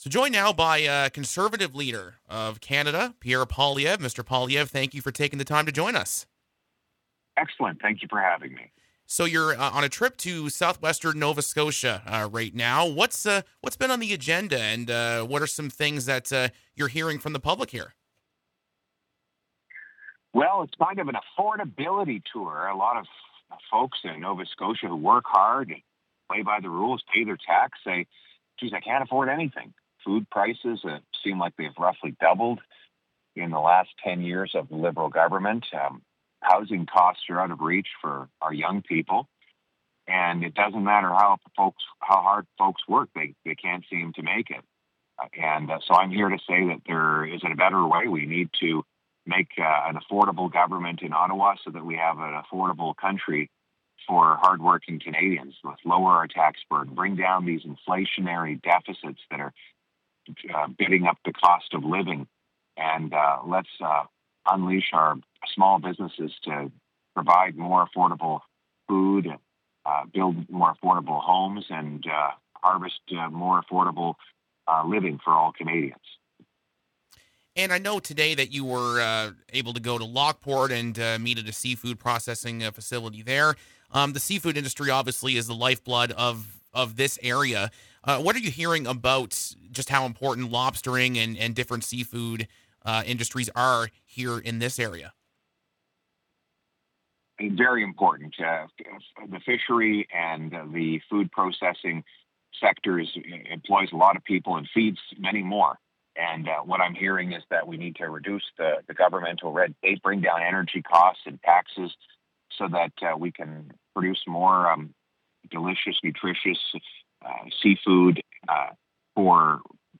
Click play below to hear a full interview with Pierre Poilievre.
pierre-poilievre-interview.mp3